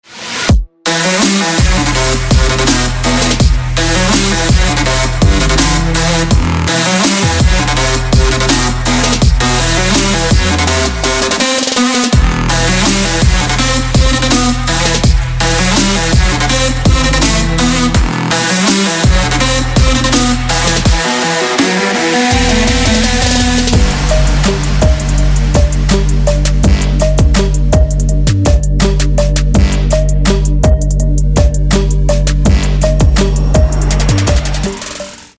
• Качество: 224, Stereo
Electronic
без слов
club
Bass
Hybrid Trap